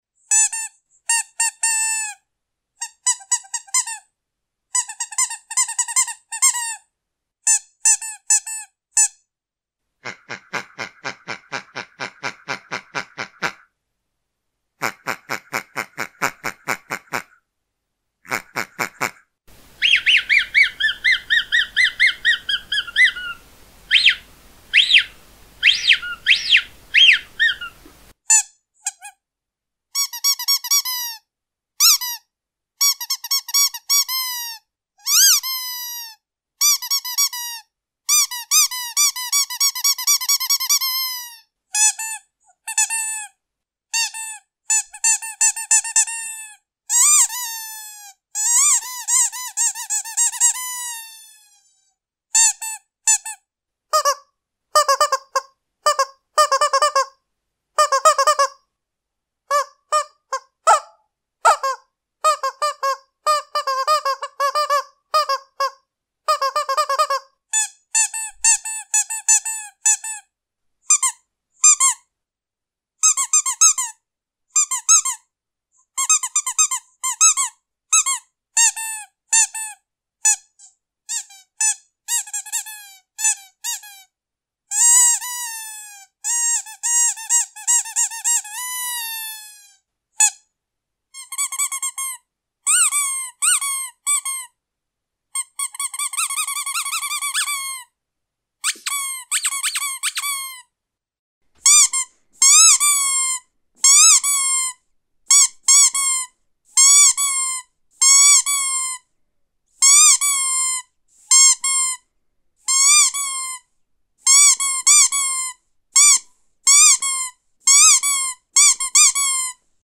На этой странице собраны звуки собак, играющих с игрушками: лай, повизгивание, рычание и другие забавные моменты.
Пищалка для собак за 2 минуты